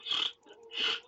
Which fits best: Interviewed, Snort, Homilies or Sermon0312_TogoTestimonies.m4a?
Snort